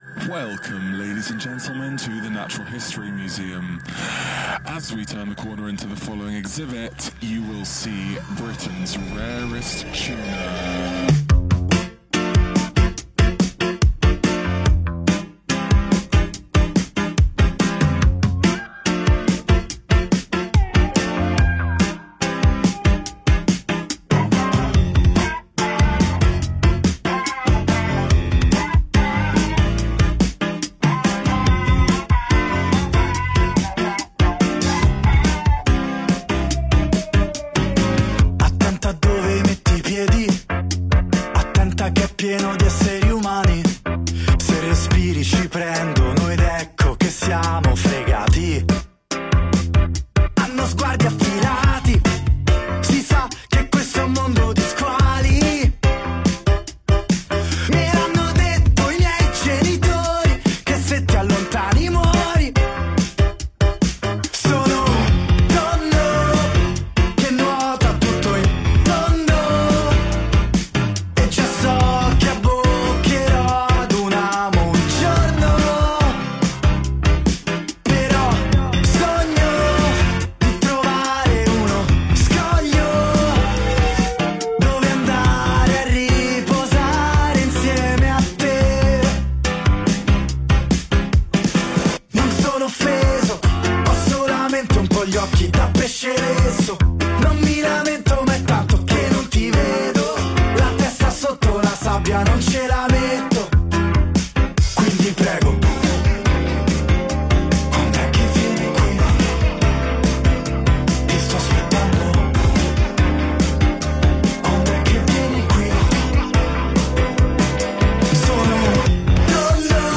Tra un brano e l’altro, suonati in acustico nei nostri studi